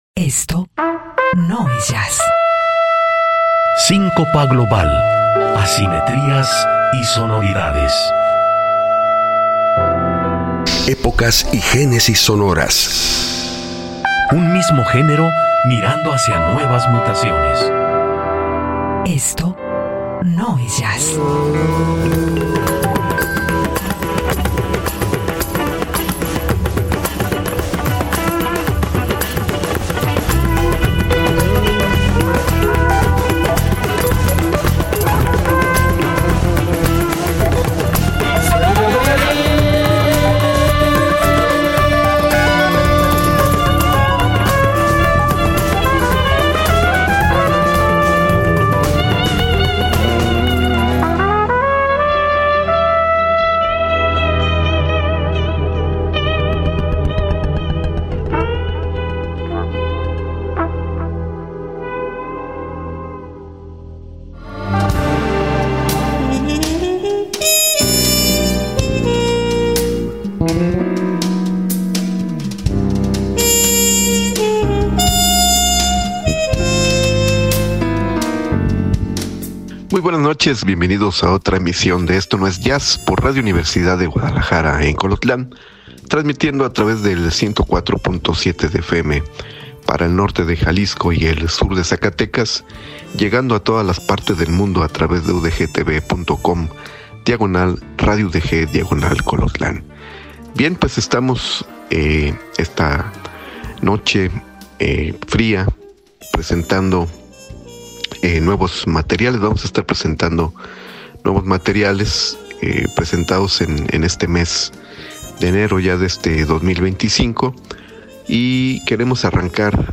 guitarrista
trompetista brasileño